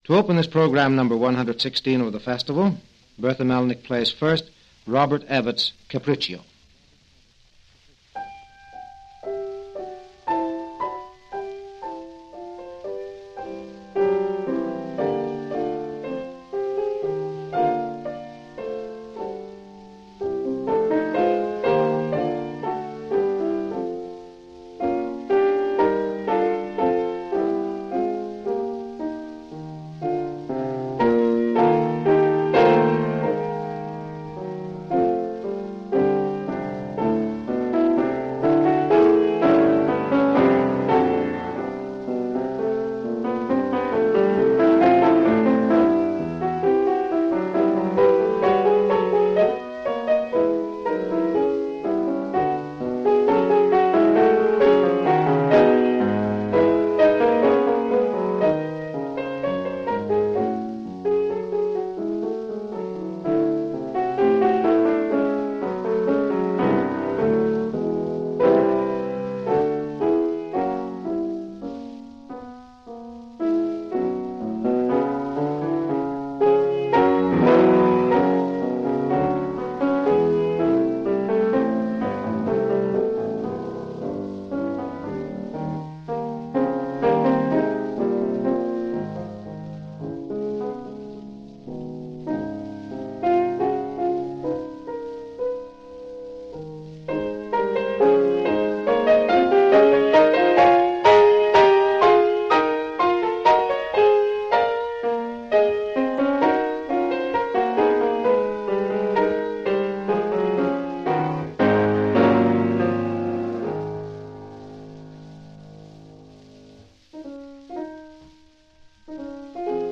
piano
1945 broadcast